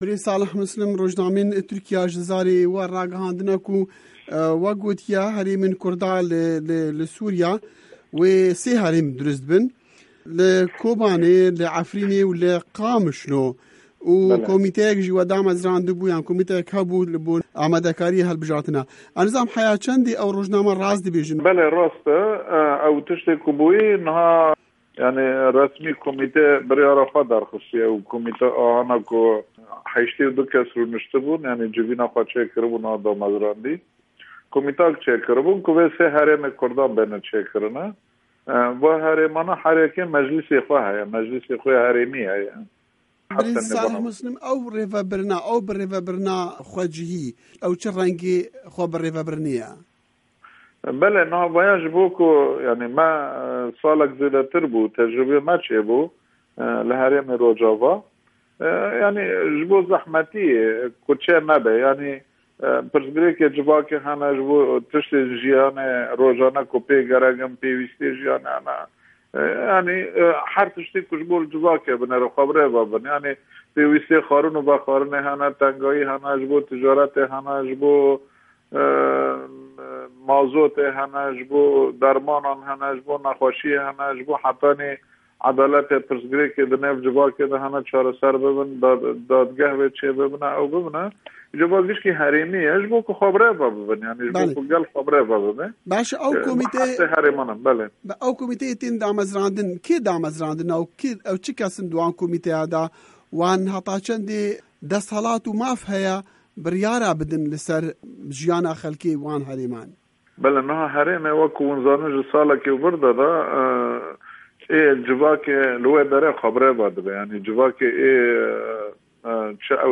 Hevpeyvîn bi Salih Mislim re